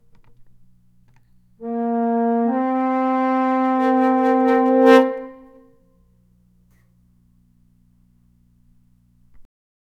A series of brassy, squeaky, and distorted tuba blasts 0:10 A tuba being played horribly, with loud, disjointed honks and flatulent brass tones 0:10
a-tuba-being-played-horri-d7zchmys.wav